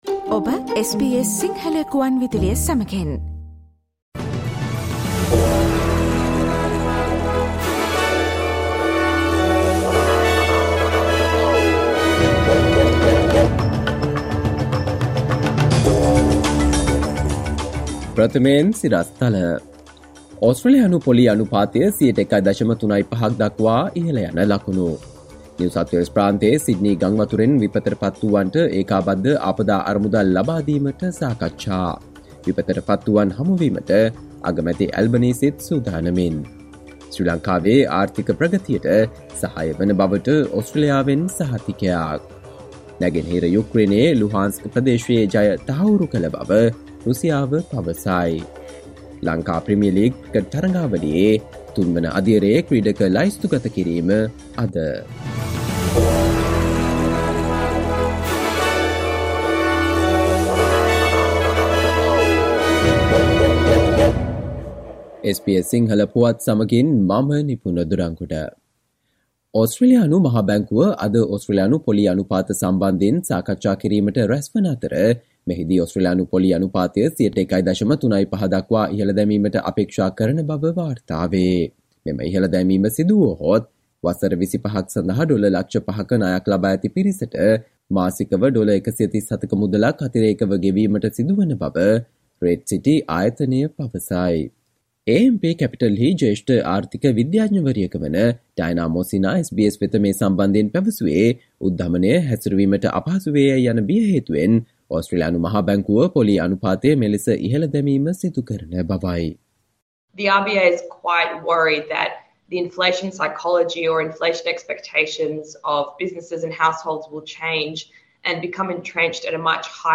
සවන්දෙන්න 2022 ජූලි 05 වන අඟහරුවාදා SBS සිංහල ගුවන්විදුලියේ ප්‍රවෘත්ති ප්‍රකාශයට...